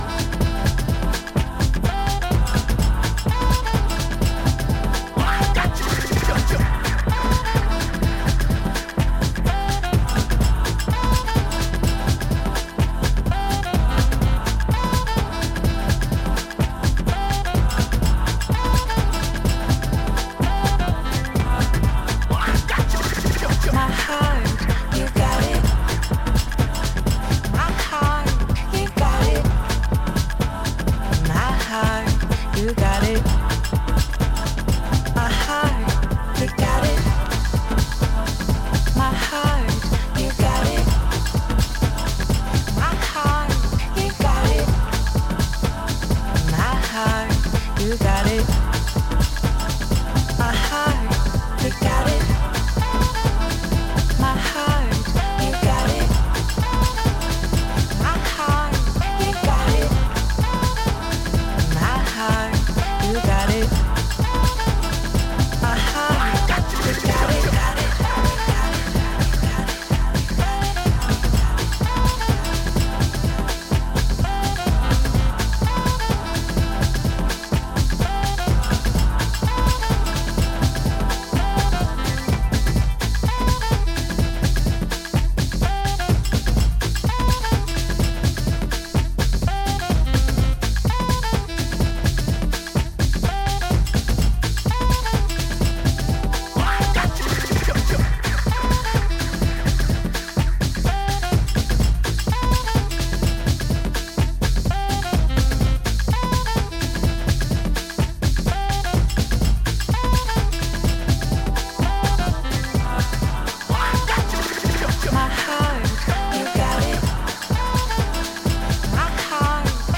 R&B